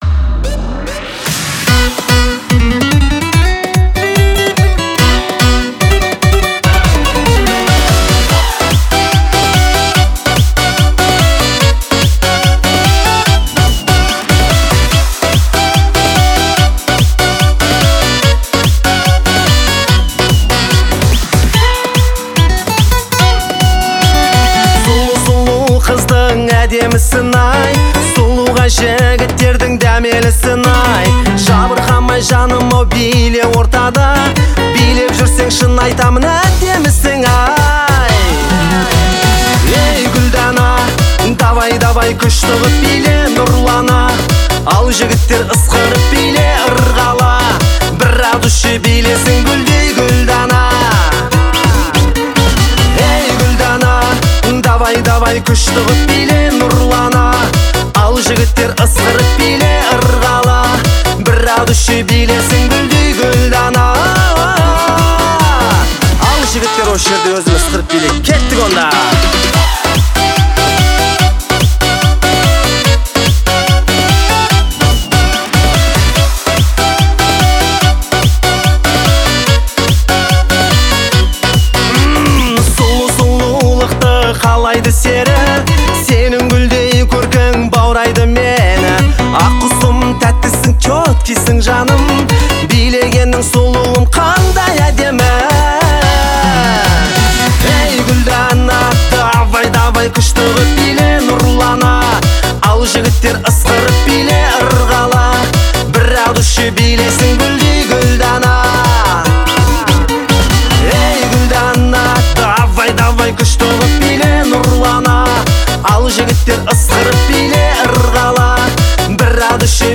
энергичная и зажигательная песня